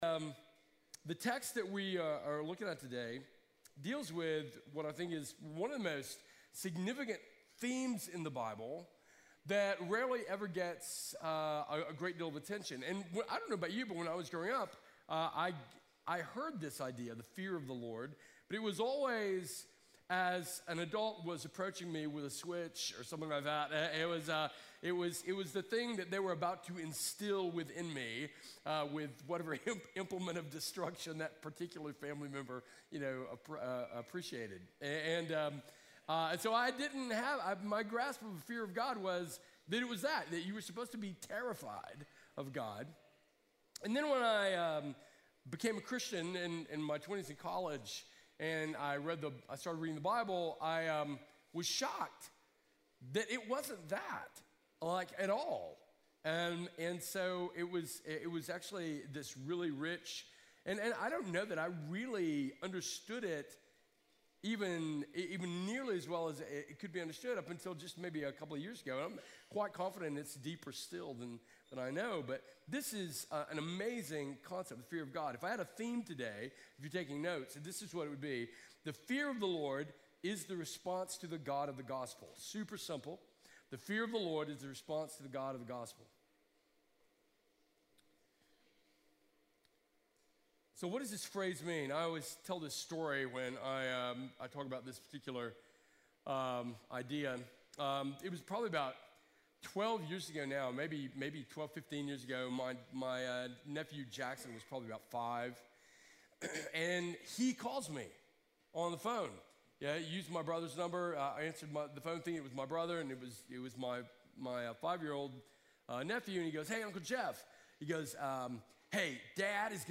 Home \ Sermons \ Other Sermons \ The Fear of the Lord The Fear of the Lord by Guest Preacher